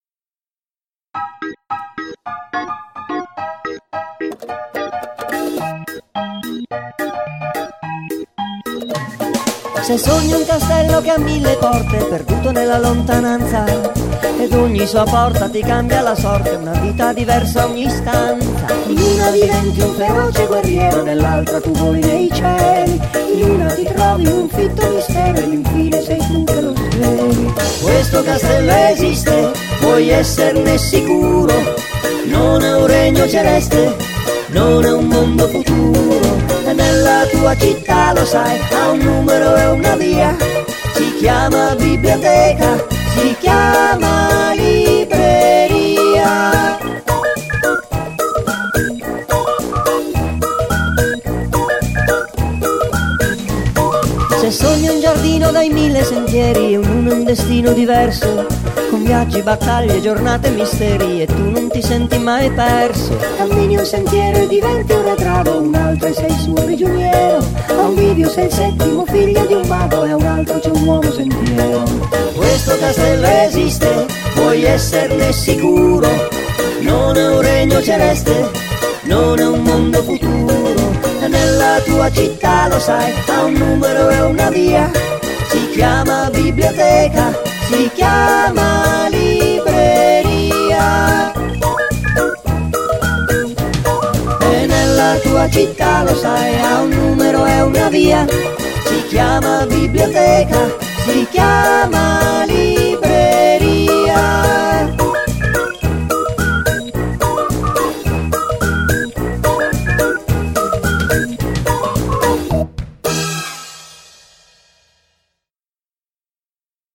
nella versione registrata e cantata in studio